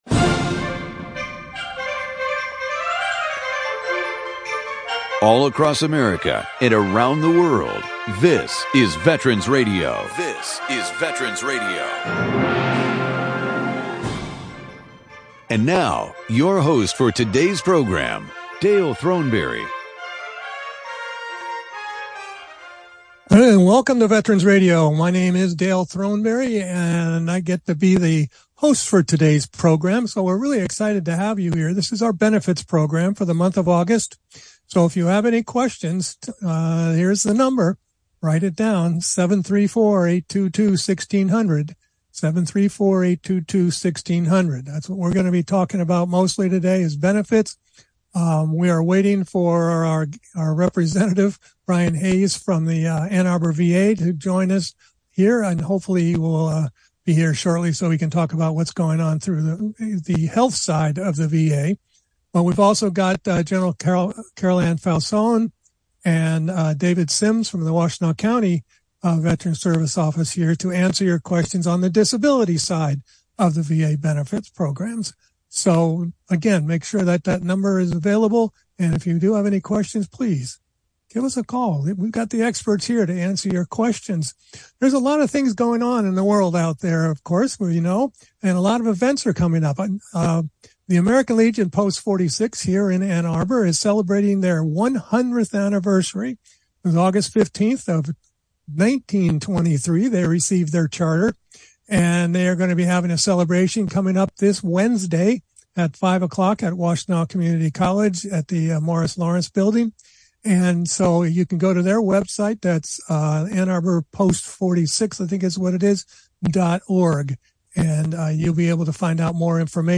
Q&A with our VA Benefits expert panel.